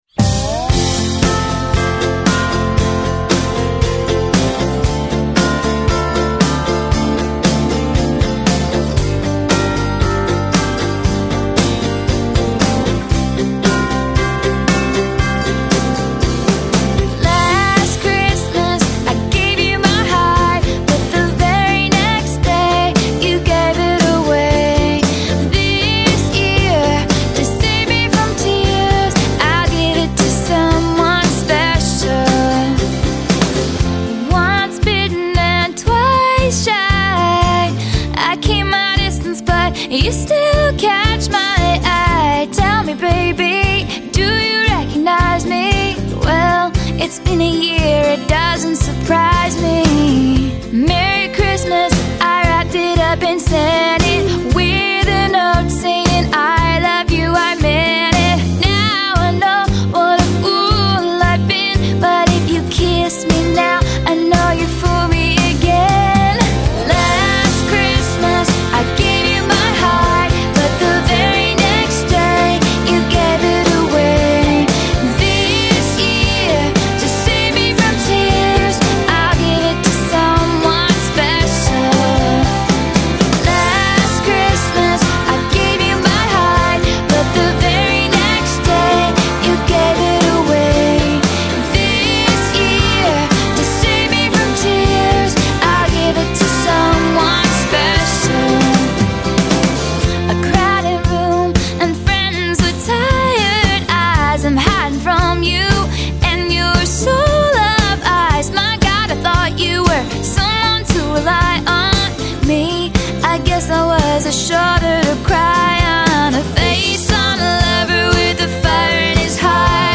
音乐风格: Holiday, Country pop